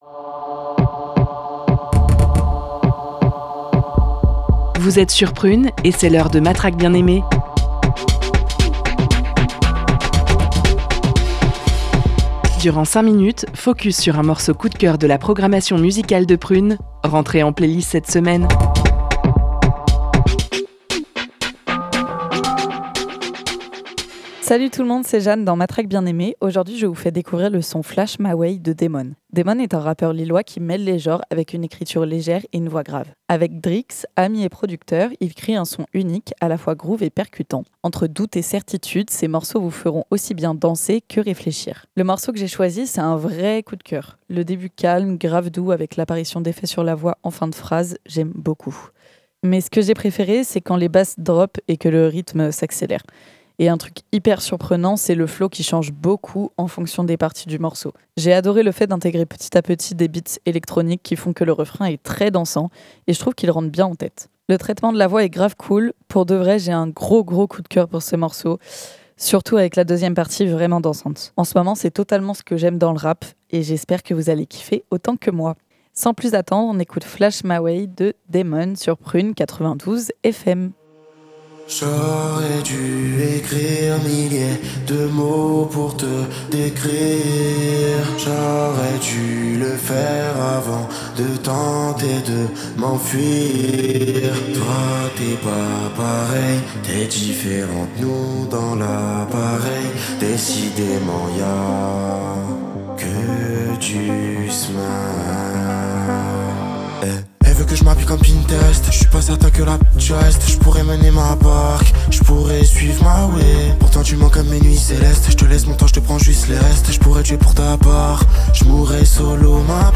une voix grave